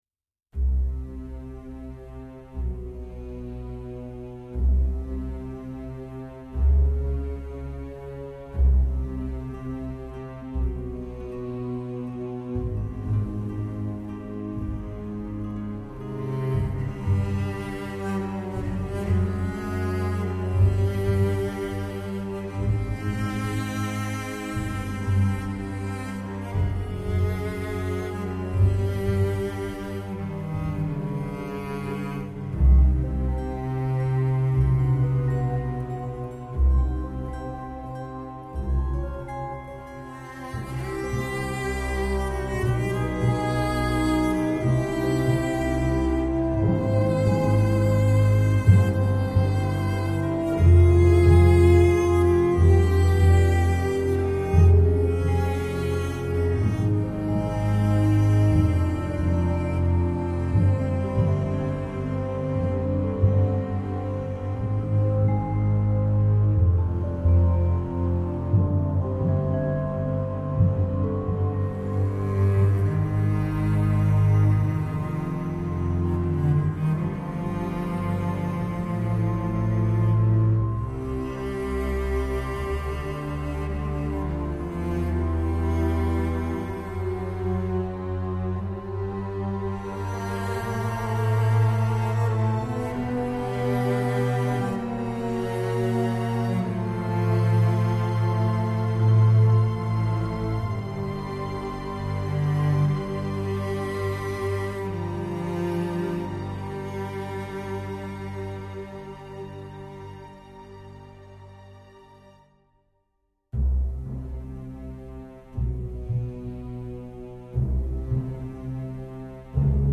solo cello melody